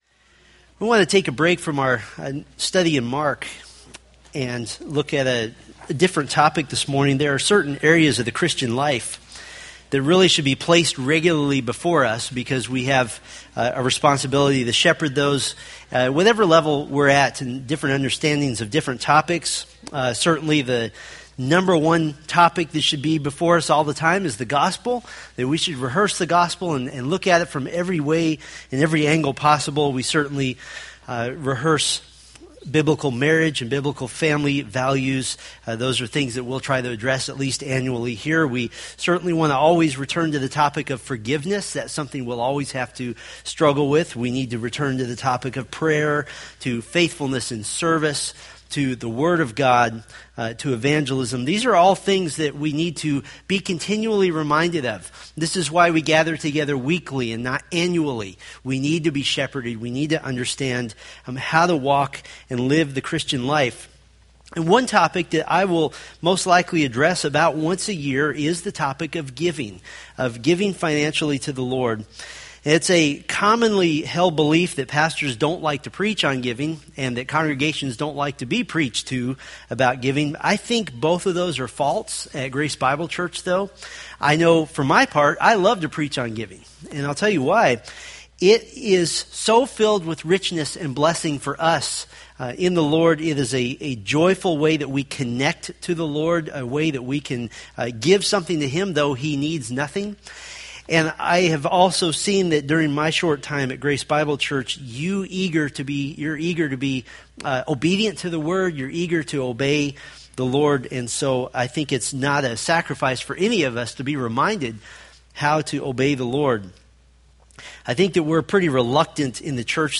From the Various 2014 sermon series.